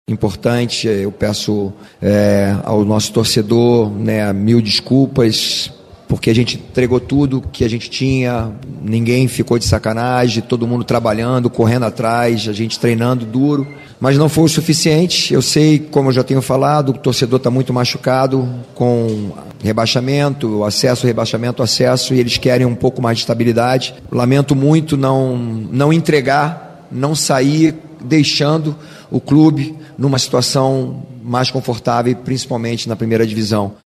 No início da entrevista coletiva que marcou esta despedida